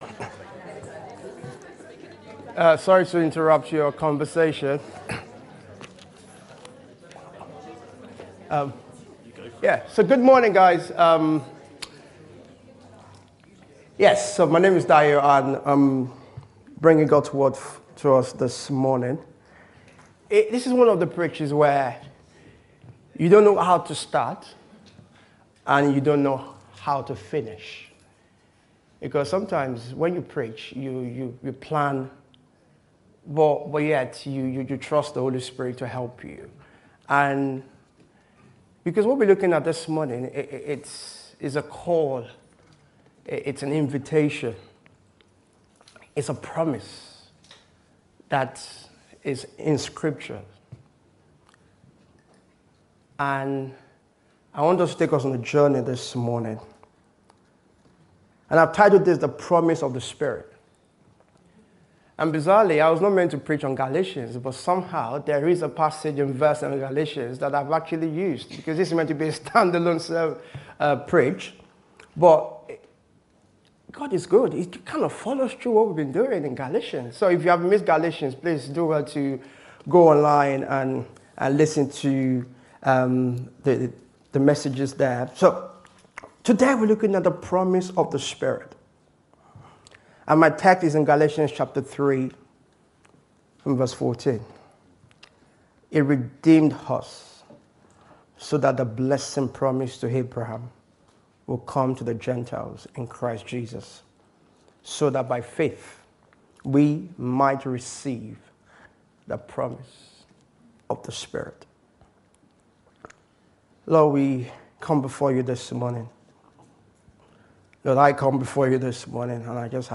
This sermon is a powerful encouragement to come to God expectantly for more of his presence.